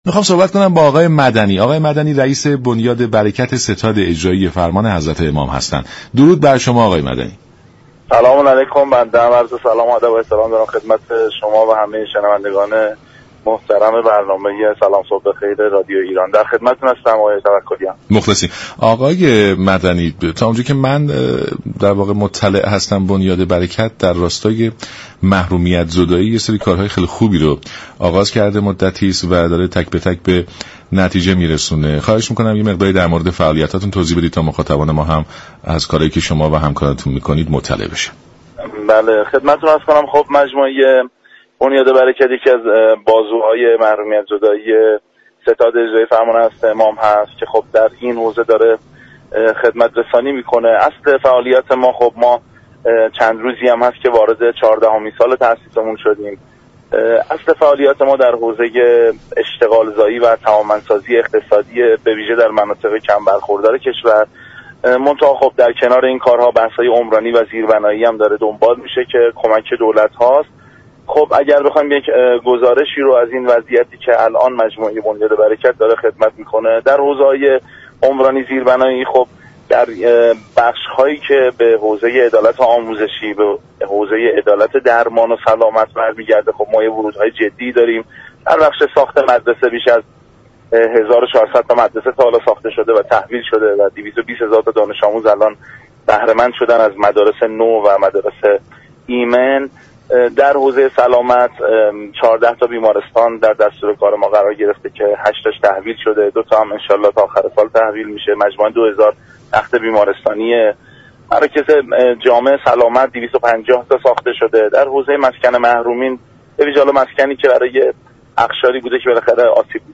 به گزارش شبكه رادیویی ایران، امیرحسین مدنی رییس بنیاد بركت ستاد اجرایی فرمان امام خمینی (ره) در برنامه سلام صبح بخیر رادیو ایران به فعالیت های این ستاد در جهت محرومیت زدایی اشاره كرد و گفت: بنیاد بركت به عنوان یكی از بازوهای محرومیت زدایی ستاد اجرایی فرمان امام خمینی (ره) در این حوزه فعالیت ها و اقدامات خوبی را انجام داده است.